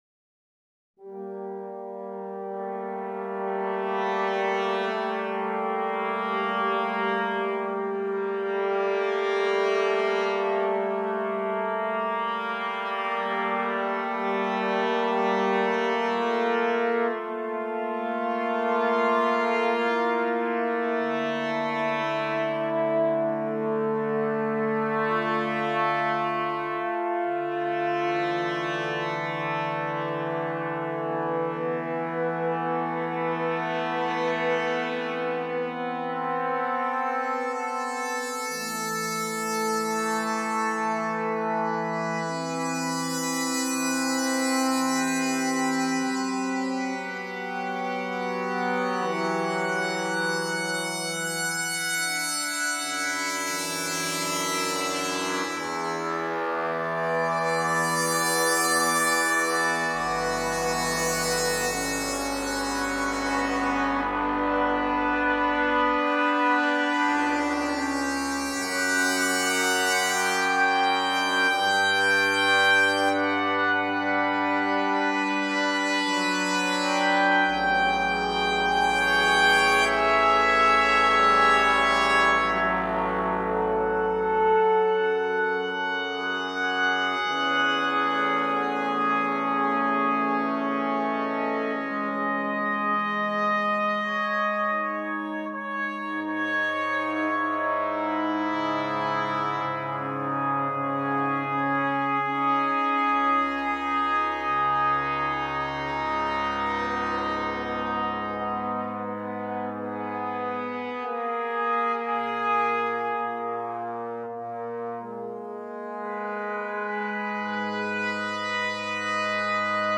“Winds of Centuries” for Brass Quintet